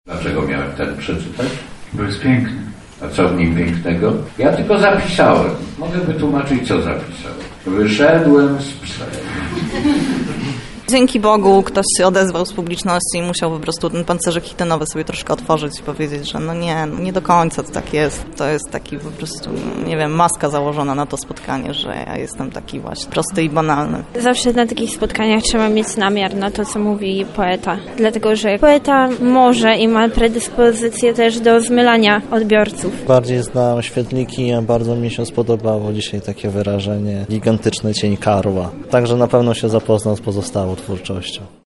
Drobne zmiany, które zaszły w życiu Marcina Świetlickiego Wczoraj w Ośrodku Brama Grodzka Teatr NN czytelnicy spotkali się z poetą.